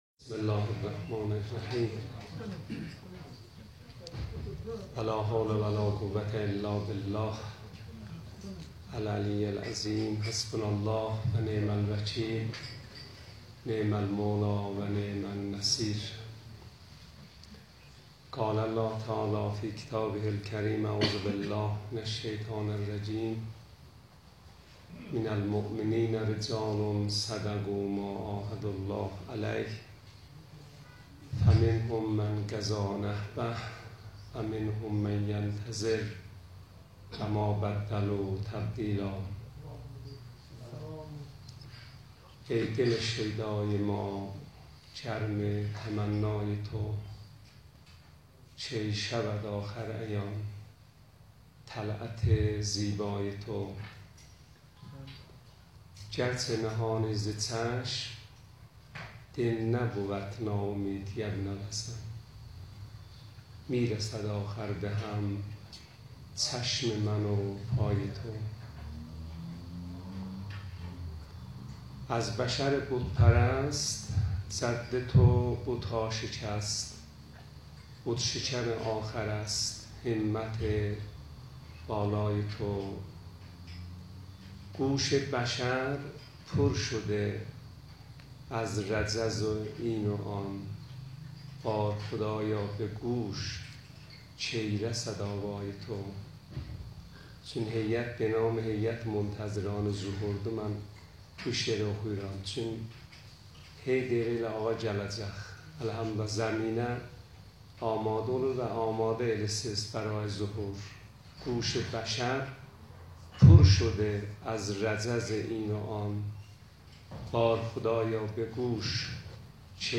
یکی از جلسات هیئت هفتگی منتظران ظهور به نیابت از شهید بزرگوار قنبر امانی وجنی برگزار گردید...